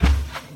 Sound / Minecraft / mob / cow / step2.ogg